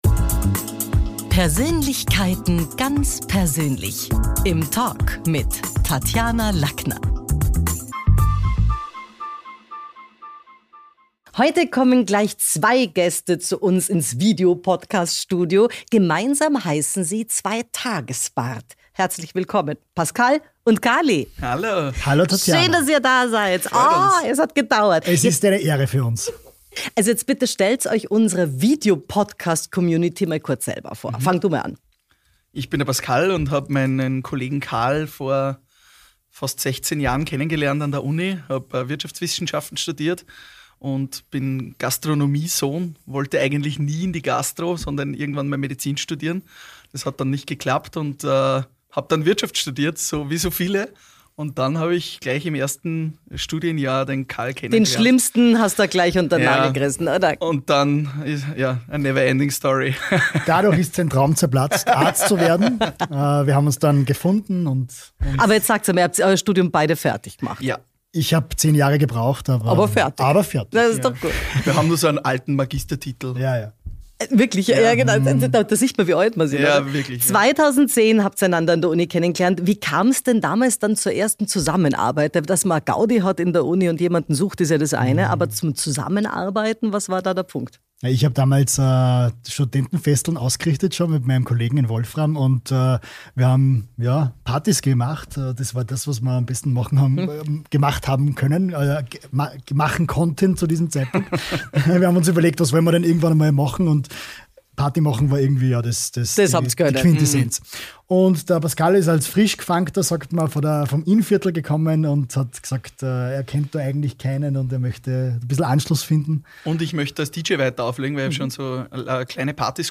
Zwei Männer mit Stimmen, die Räume füllen, noch bevor das Mikro an ist. Wir sprechen über die Kunst, Menschen in Sekunden zu gewinnen, über Lampenfieber, Eitelkeit, Timing und das gut geprobte Gefühl namens Authentizität. Ein Gespräch aus der Praxis für die Praxis.